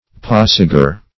Search Result for " passager" : The Collaborative International Dictionary of English v.0.48: Passager \Pas"sa*ger\, n. [See Passenger .]
passager.mp3